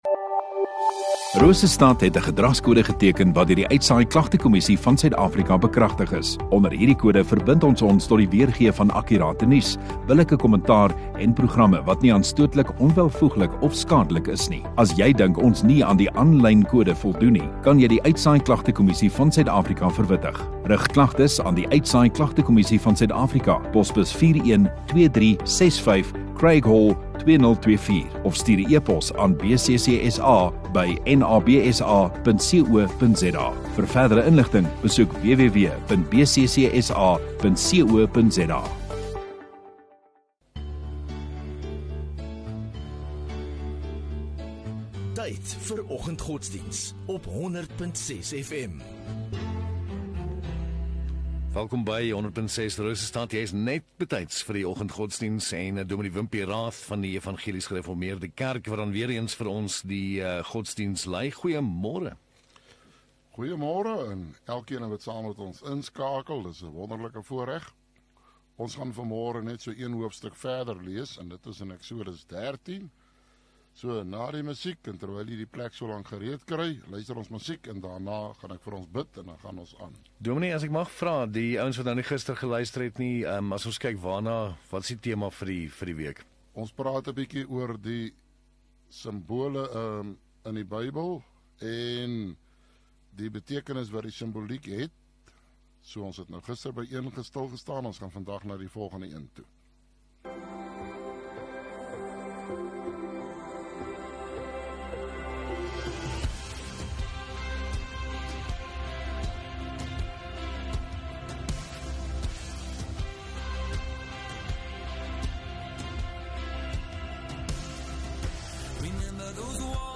17 Oct Dinsdag Oggenddiens